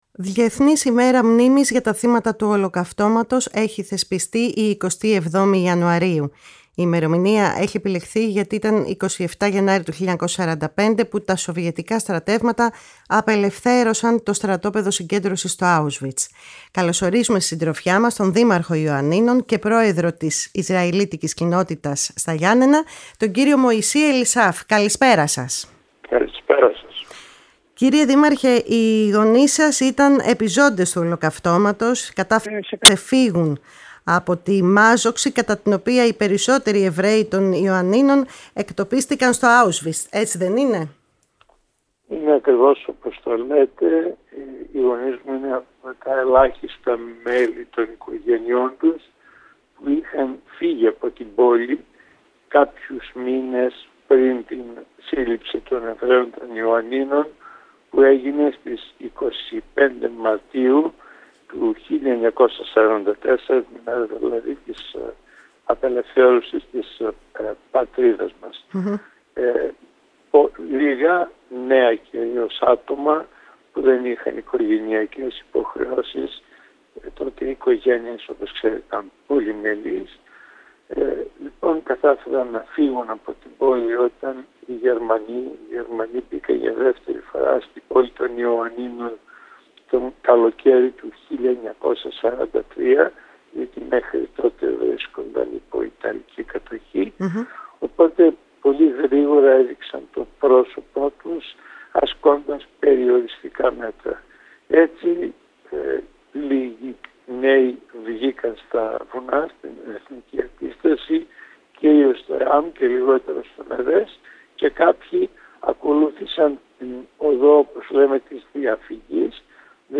Διεθνής Ημέρα Μνήμης για τα Θύματα του Ολοκαυτώματος έχει θεσπιστεί η 27η Ιανουαρίου και ο Δήμαρχος Ιωαννιτών και διεθνούς φήμης καθηγητής παθολογίας κ. Μωυσής Ελισάφ μίλησε στη “Φωνή της Ελλάδας” και συγκεκριμένα στην εκπομπή “Κουβέντες μακρινές”